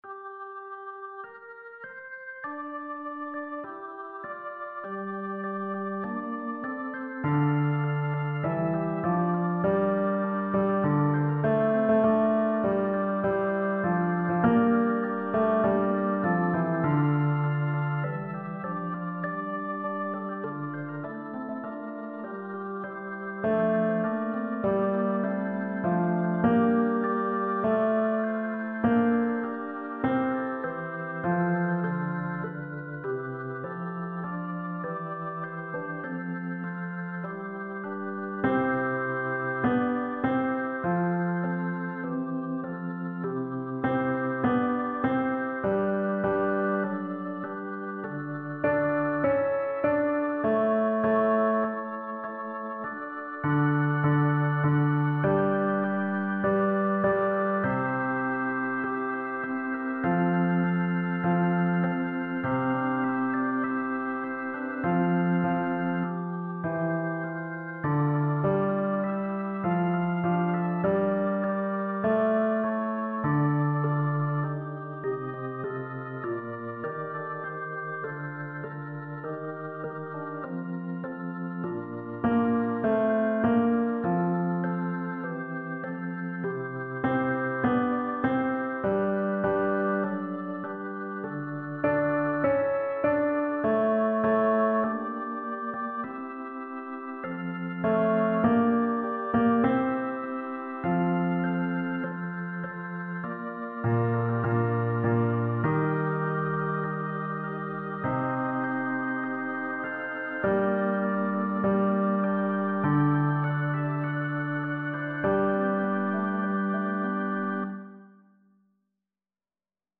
Fichiers de Travail des Basses